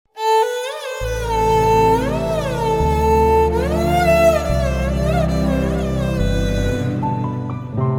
Category: Telugu Ringtones